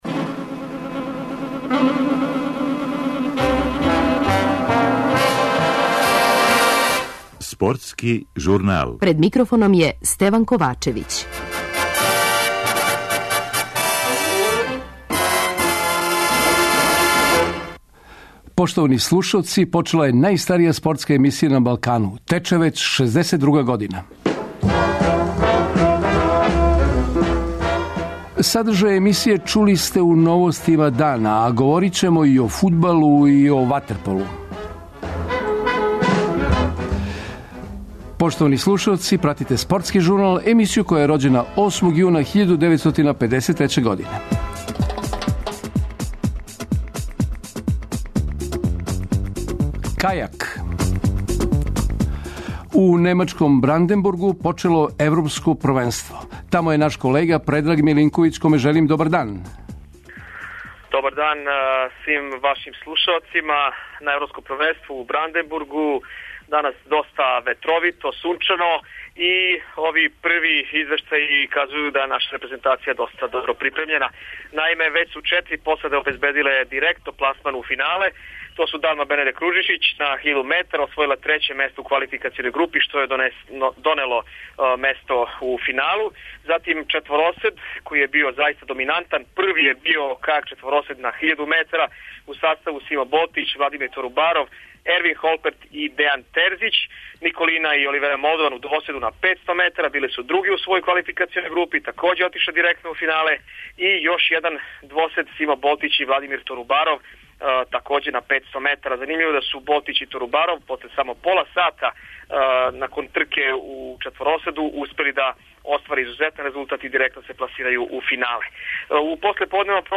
У најстаријој спортској емисији на Балкану (8.6.1953): У немачком Бранденбургу данас почело Европско првенство.